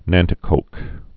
(năntĭ-kōk)